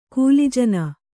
♪ kūli jana